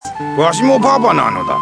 パパの声１ (mp3形式 13KB)